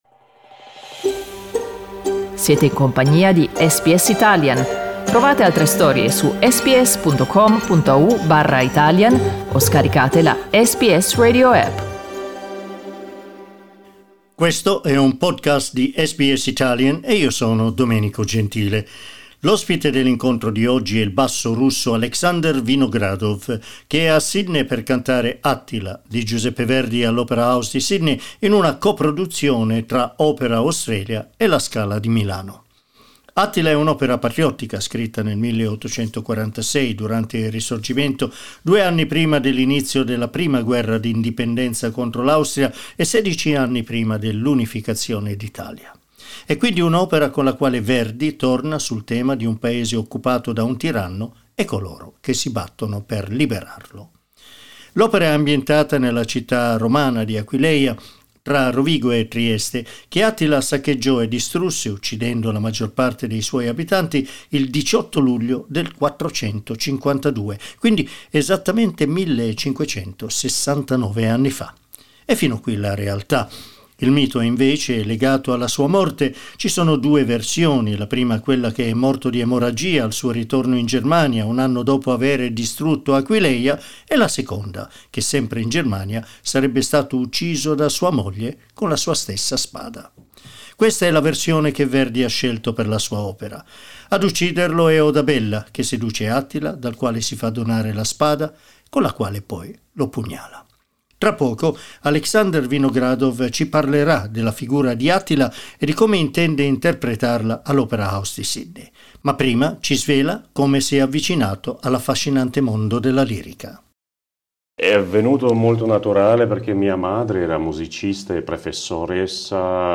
In questa conversazione ci racconta di come ha iniziato la sua carriera di cantante lirico che lo ha visto debuttare ad appena 21 anni al Teatro Bolshoi di Mosca. Ascolta l'intervista: LISTEN TO Il basso Alexander Vinogradov in Australia per l'Attila di Verdi SBS Italian 13:24 Italian Le persone in Australia devono stare ad almeno 1,5 metri di distanza dagli altri.